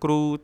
7-s1-kruu-careful.wav